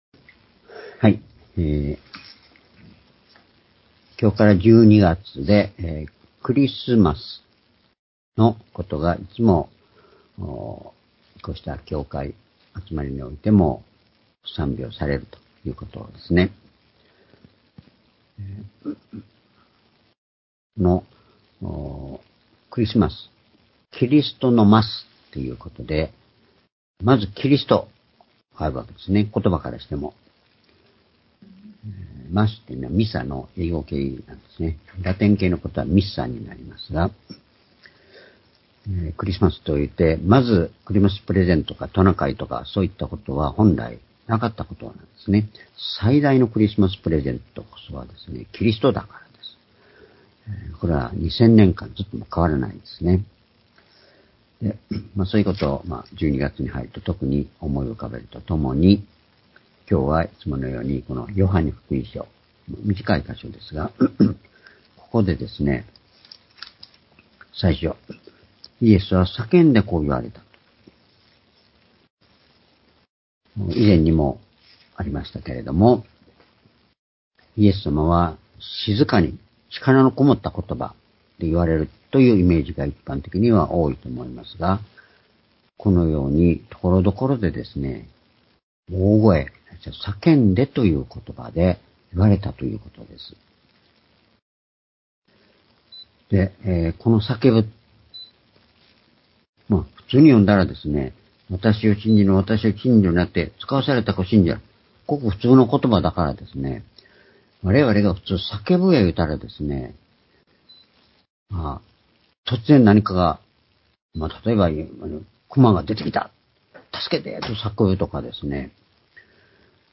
主日礼拝日時 ２０２３年12月3日（主日礼拝） 聖書講話箇所 「キリストを信じ、見るとは、神を信じ、見ること」 ヨハネ １２の４４－４６ ※視聴できない場合は をクリックしてください。